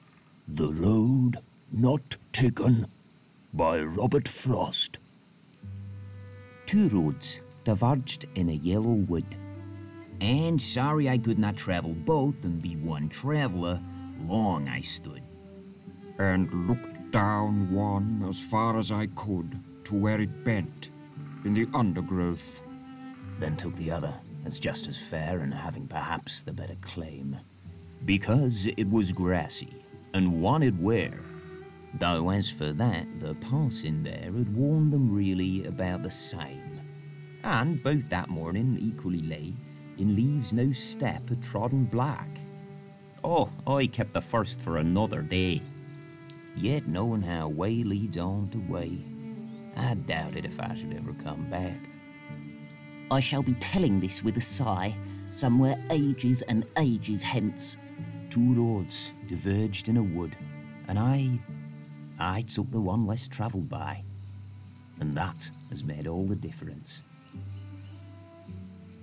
He has a lovely natural, warm and friendly tone to his voice too.
Gender Male
Accent Australian Birmingham Cockney German Heightened RP New York Scottish Southern American Standard English R P Standard U S Yorkshire
Characters & Animation Clips
Animation_Reel1.mp3